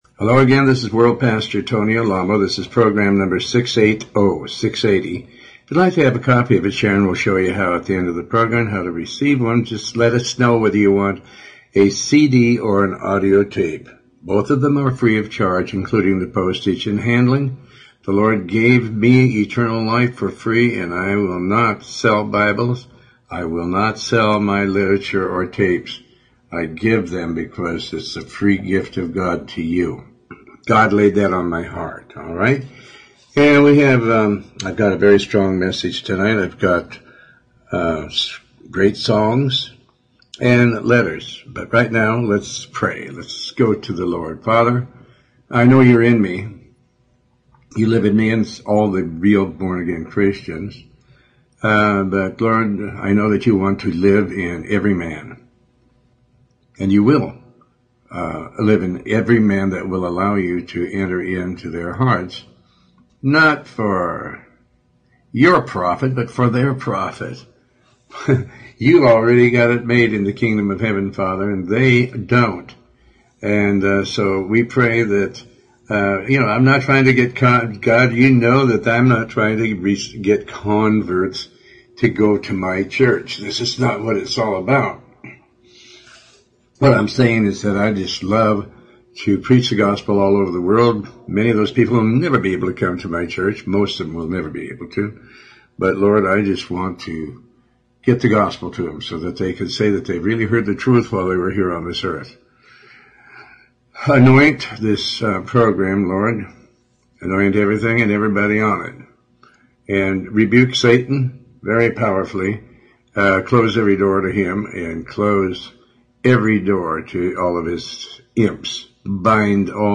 Talk Show Episode, Audio Podcast, Tony Alamo and The Spirit of God mortifies the deeds of the flesh.